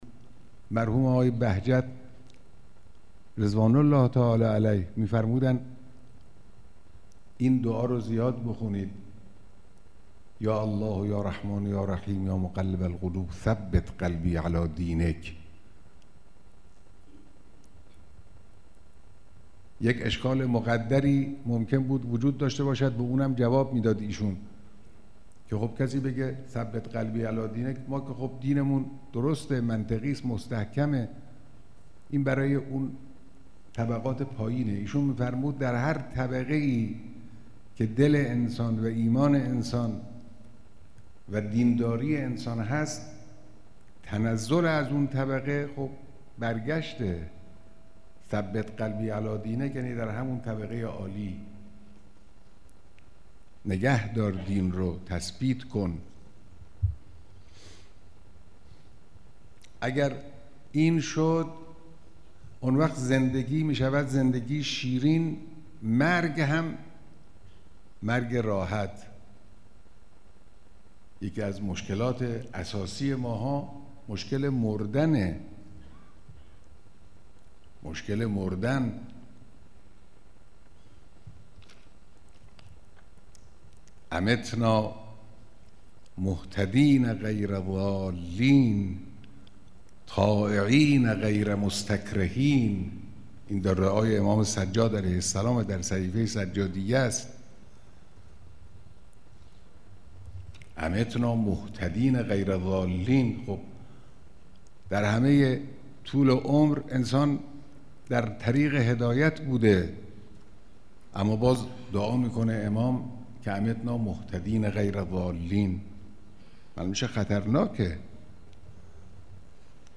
رهبر انقلاب در دیدار با اساتید، فضلا و طلاب نخبه‌ حوزه علمیه قم در تاریخ ۱۳۸۹/۸/۲ در ذیل شرح حدیثی، توصیه‌ مرحوم آیت‌الله بهجت را برای خواندن دعای «یا الله یا رحمان یا رحیم یا مقلب القلوب ثبت قلبی علی دینک» بیان کردند و فرمودند: